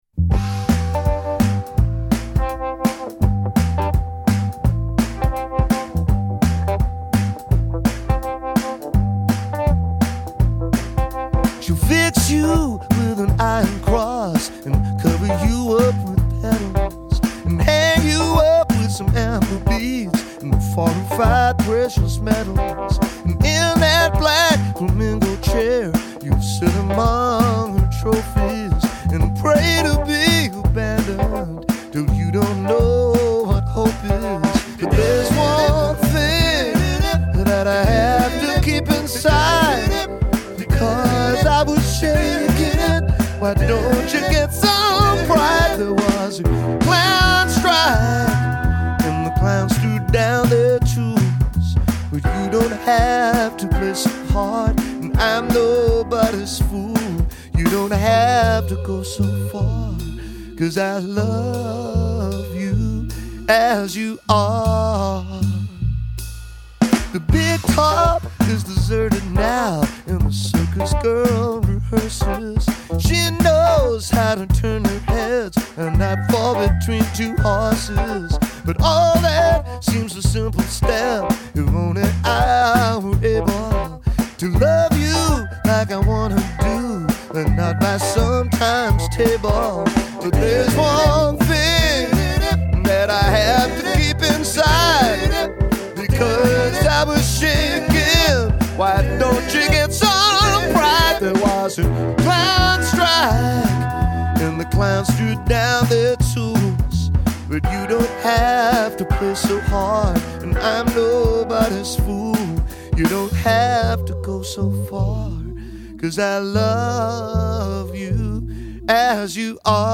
great infusion of pop, jazz and R&B